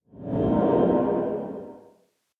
sounds / ambient / cave
cave12.mp3